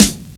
• Prominent Urban Snare One Shot G Key 31.wav
Royality free snare drum sound tuned to the G note. Loudest frequency: 3367Hz
prominent-urban-snare-one-shot-g-key-31-h96.wav